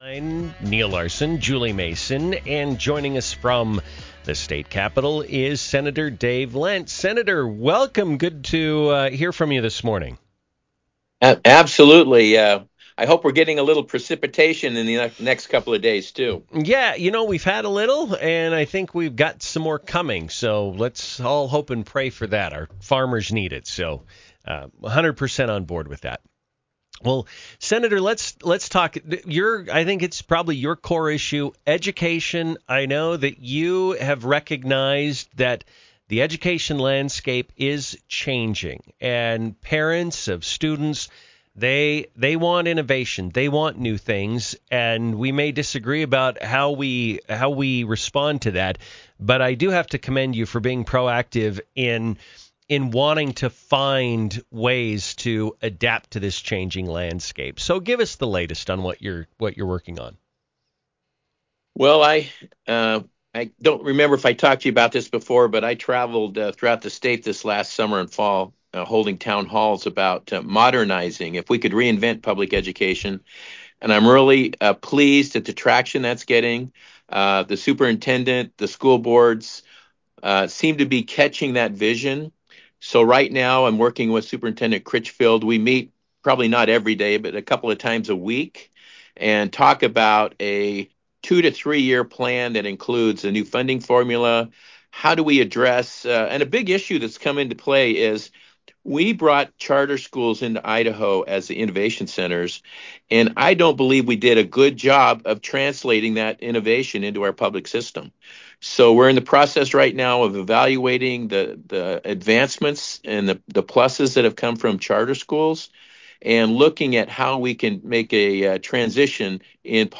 INTERVIEW: Senator Dave Lent - Education Overhaul, Budget, Energy Goals - Newstalk 107.9